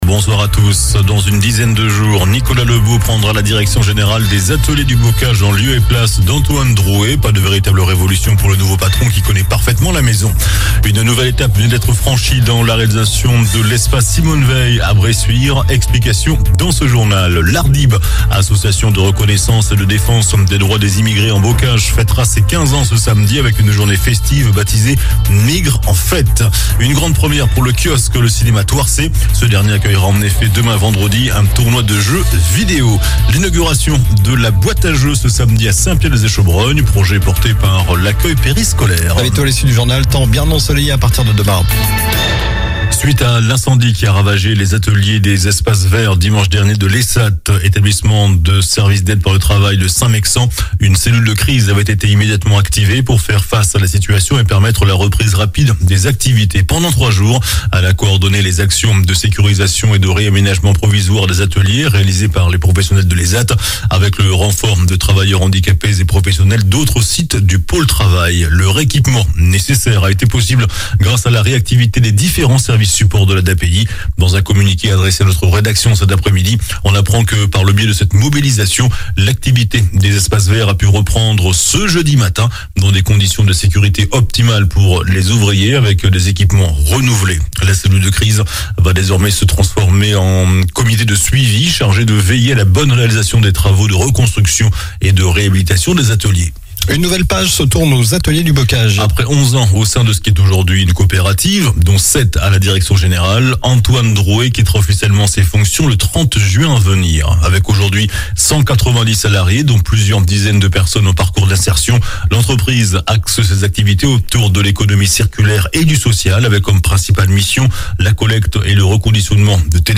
JOURNAL DU JEUDI 22 JUIN ( SOIR )